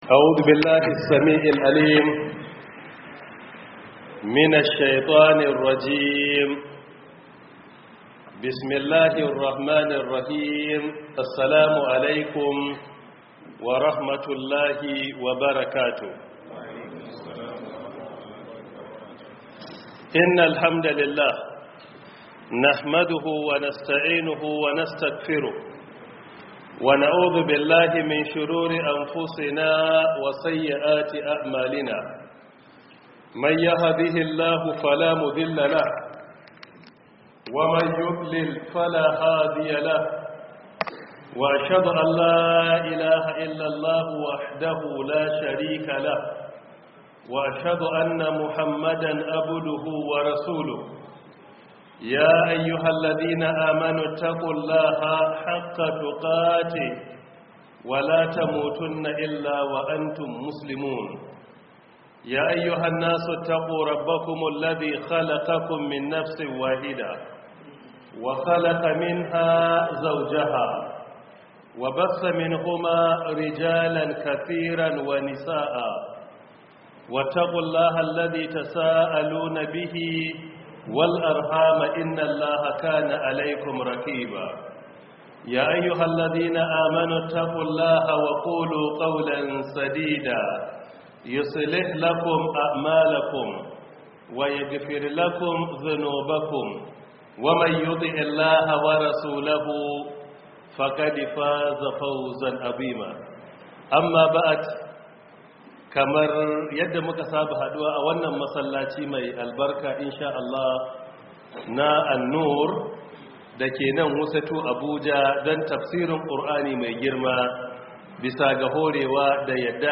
Audio lecture by Professor Isa Ali Ibrahim Pantami — 1447/2026 Ramadan Tafsir